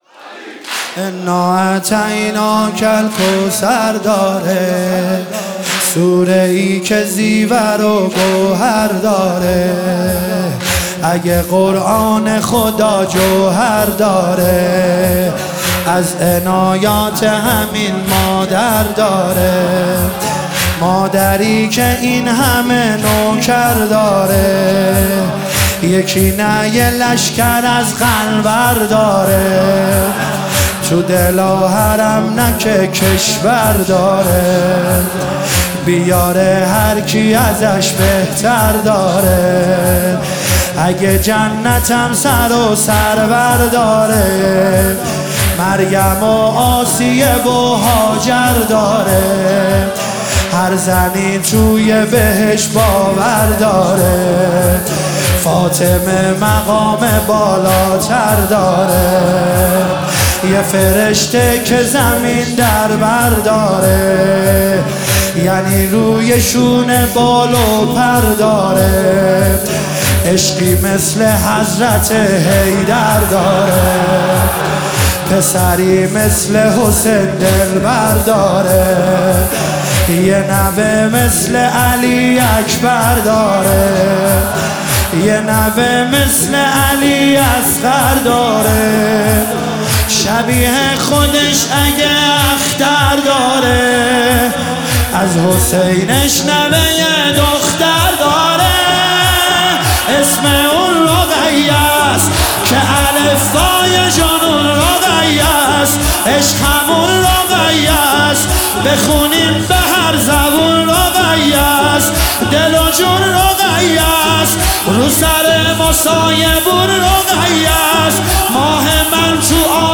مداحی واحد
شب اول فاطمیه 1403 - محفل زوارالبقیع طهران